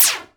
woosh_b.wav